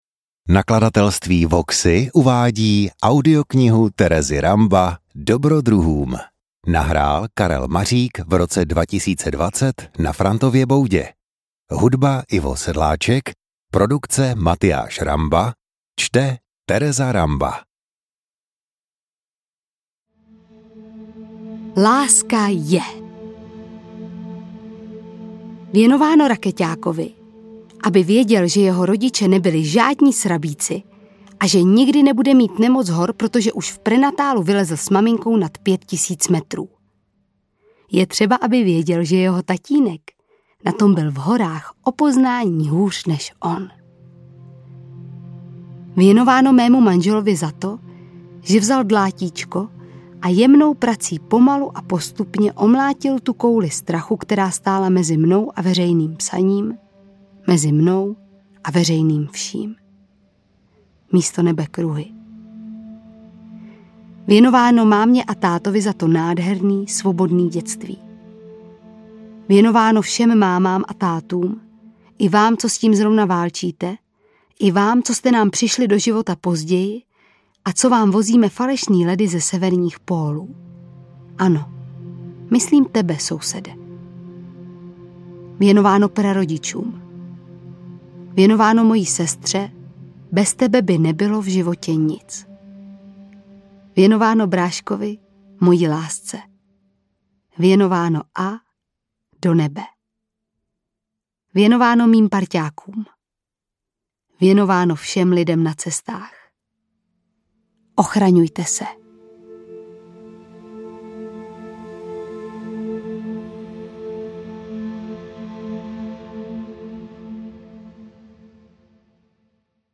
Interpret:  Tereza Ramba
Přidanou hodnotou je, že knihu čte svým osobitým stylem sama autorka.
AudioKniha ke stažení, 29 x mp3, délka 5 hod. 18 min., velikost 291,8 MB, česky